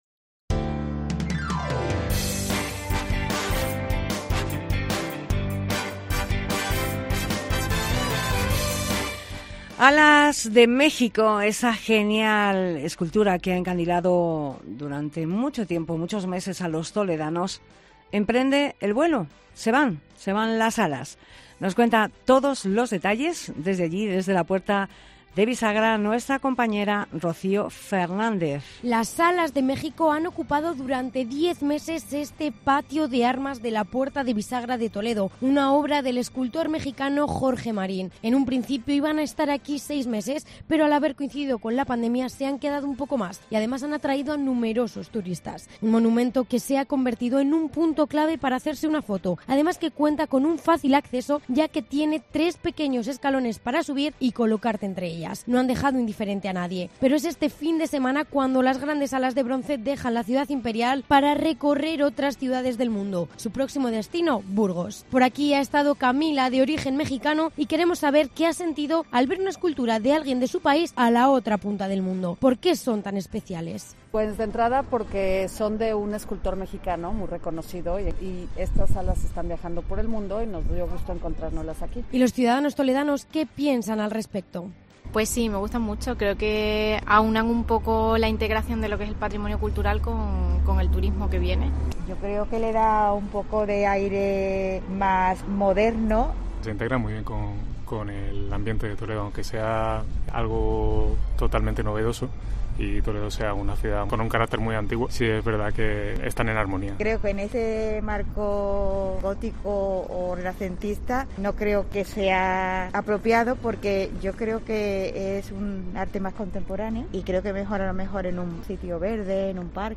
REPORTAJE ALAS DE MÉXICO